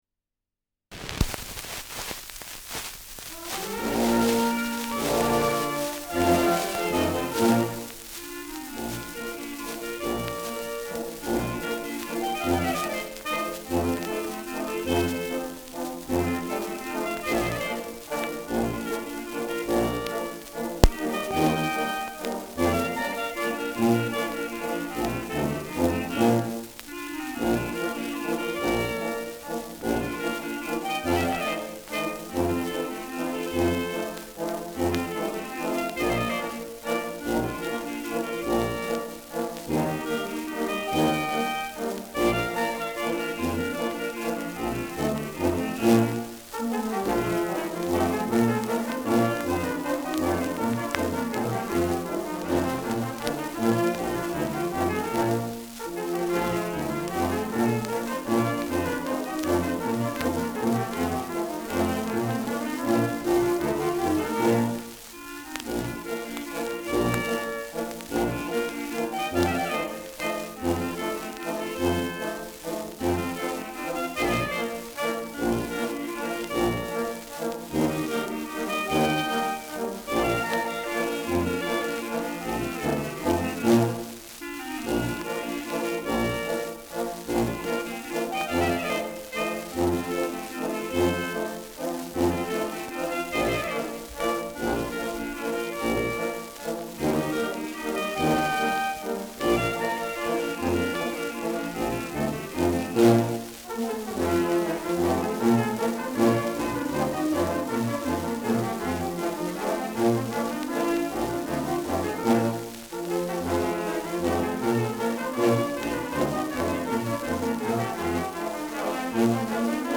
Schellackplatte
Deutlich abgespielt : Erhöhtes Grundrauschen : Gelegentliches Knacken : Leichtes Leiern
Kapelle Jais (Interpretation)
[München] (Aufnahmeort)